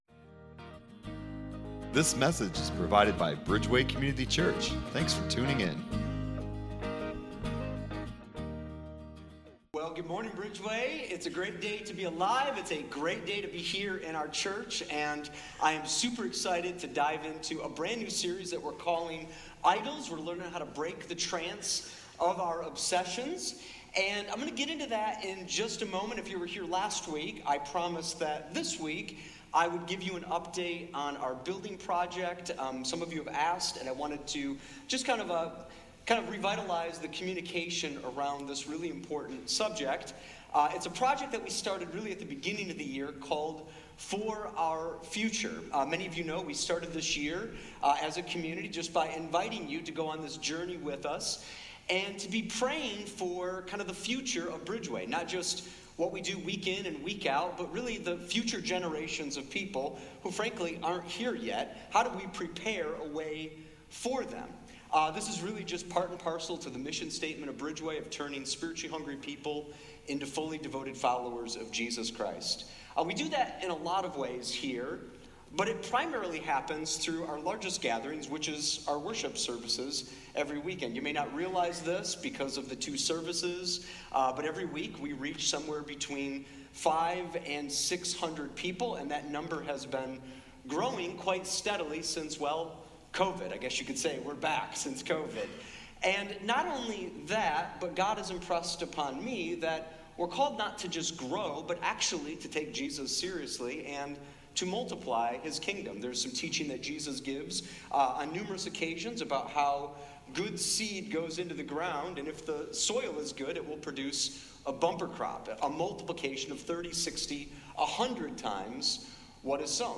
Download Sermon Discussion Guide